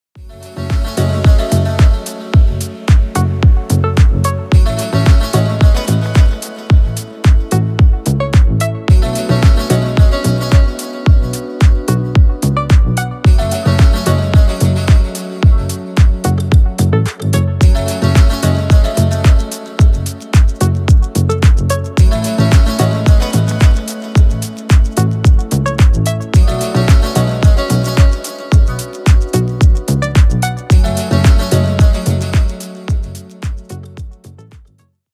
Танцевальные
без слов